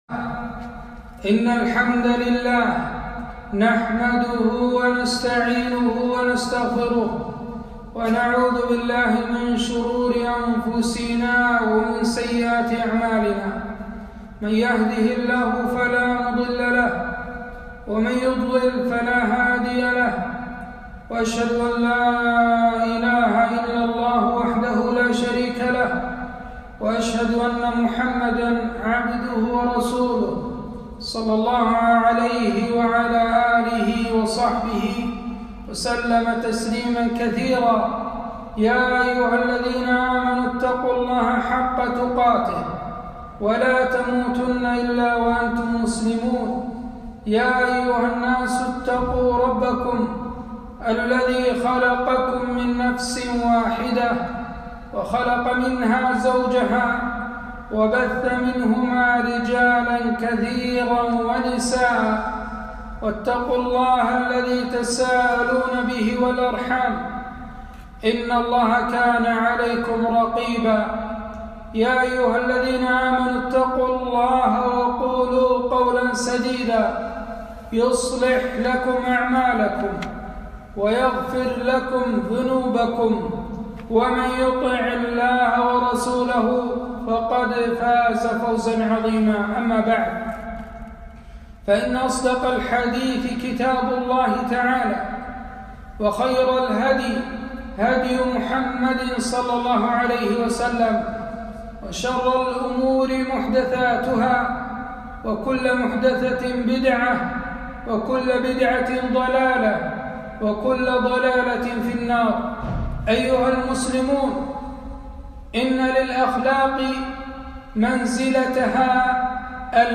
خطبة - من أخلاق المؤمنين التغافل